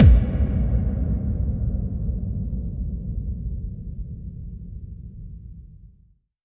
VEC3 FX Reverbkicks 15.wav